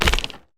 Minecraft Version Minecraft Version 1.21.5 Latest Release | Latest Snapshot 1.21.5 / assets / minecraft / sounds / mob / creaking / creaking_sway2.ogg Compare With Compare With Latest Release | Latest Snapshot
creaking_sway2.ogg